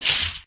sounds / material / human / step